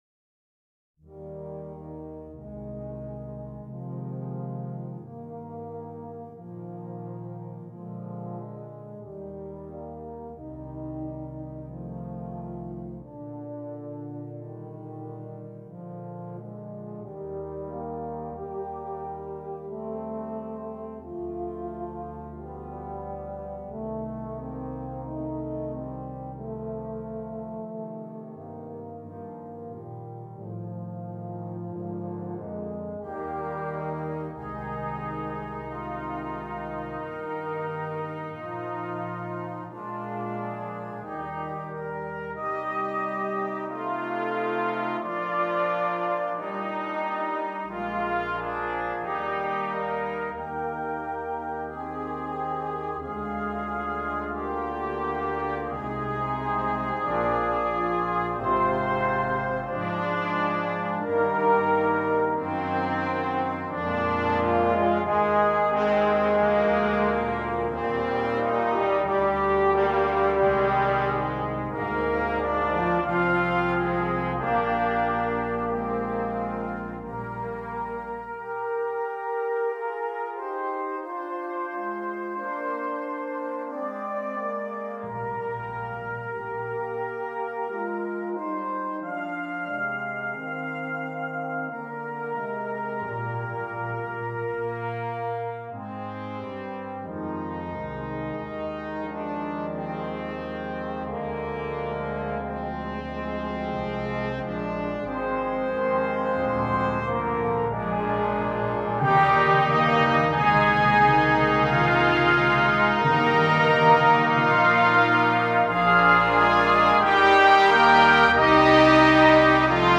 Ten Piece Brass Ensemble